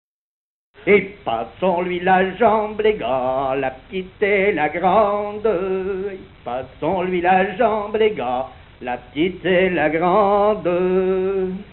gestuel : danse
Genre énumérative
Catégorie Pièce musicale inédite